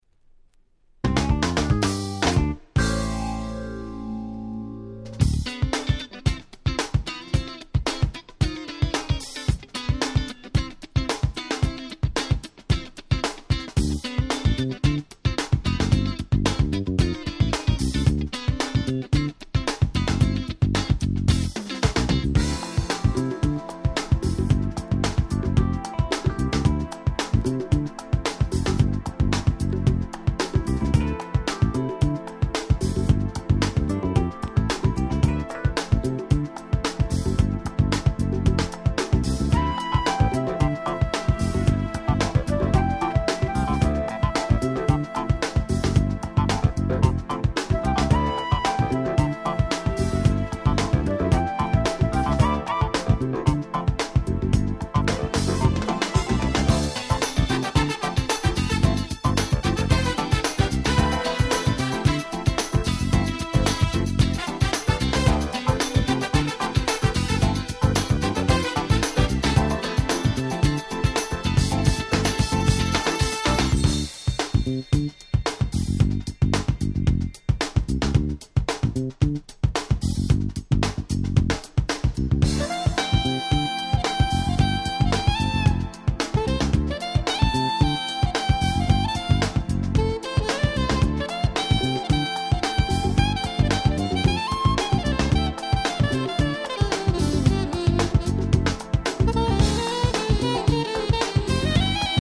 92' Acid Jazz Classics !!